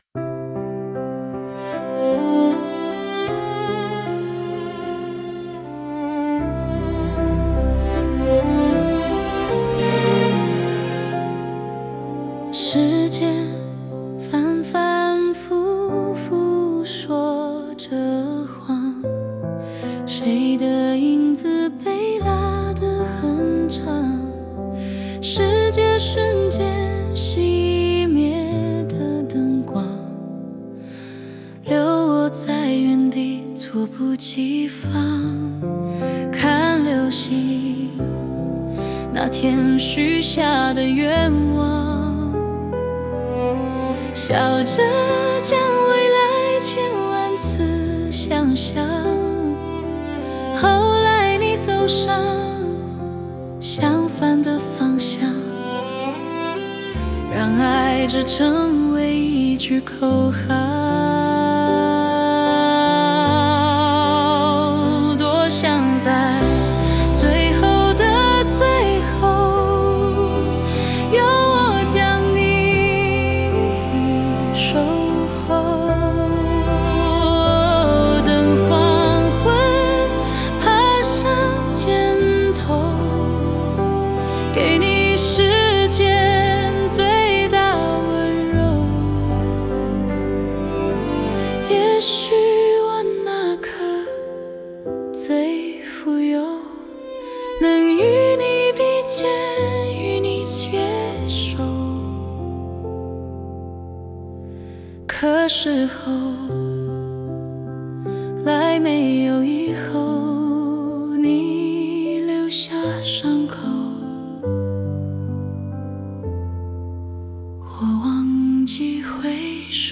因为原曲里面有配乐和人声，可能比较混乱。
用AI做了歌后，想保存在乐谱的形式下。